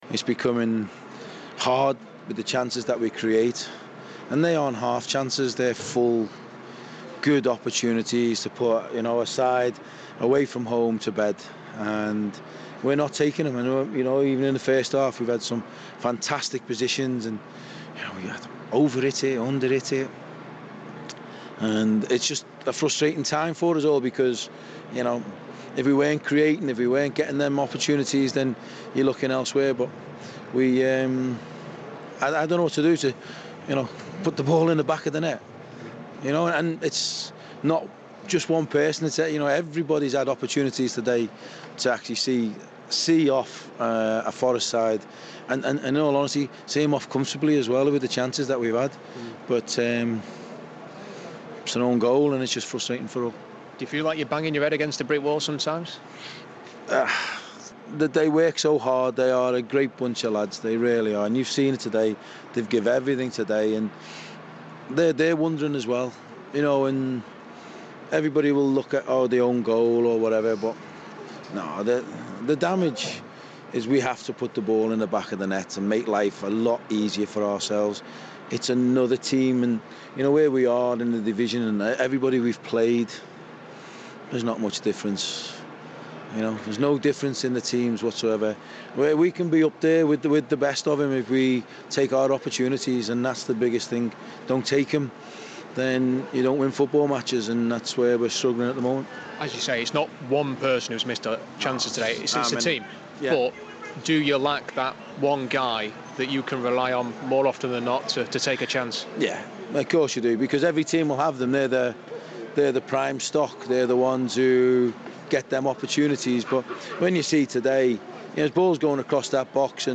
interview after a 1-0 defeat to Nottm Forest